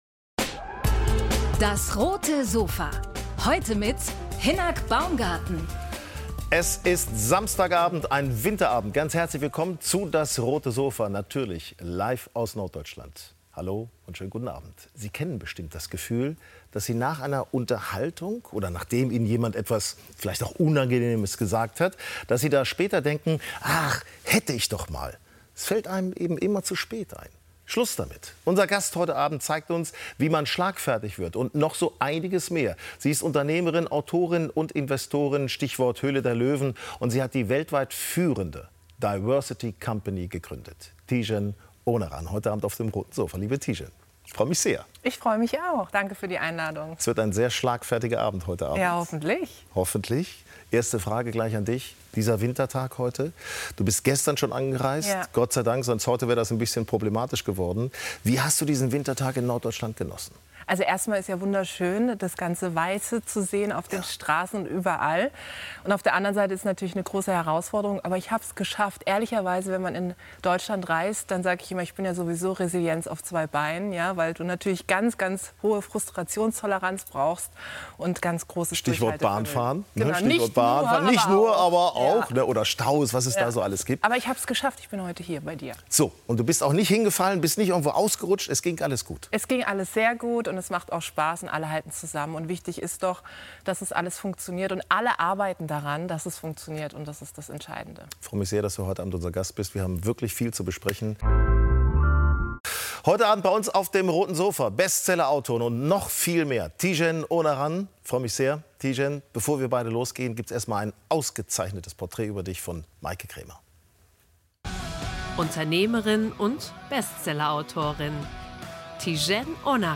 Unternehmerin und Autorin Tijen Onaran im Sofa-Talk ~ DAS! - täglich ein Interview Podcast